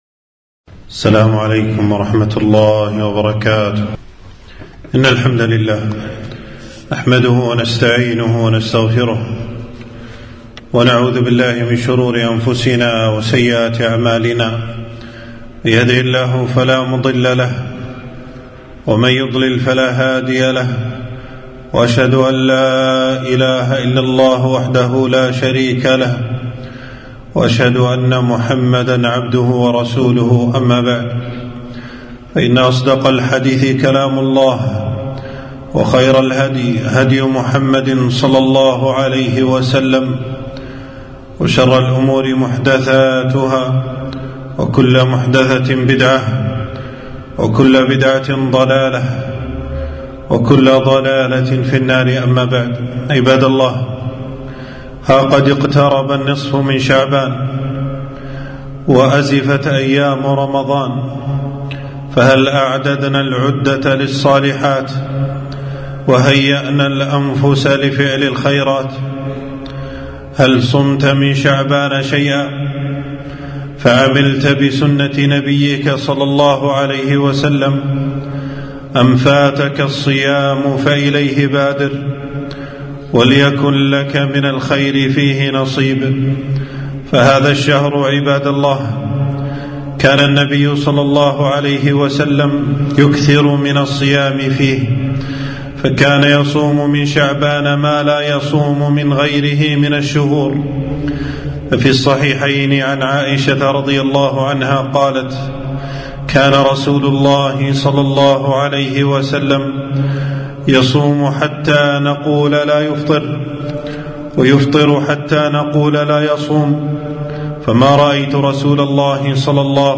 خطبة - تنبيهات تختص بالنصف من شعبان